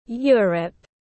Europe /ˈjʊə.rəp/